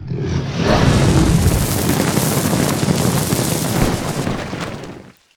fireblow.ogg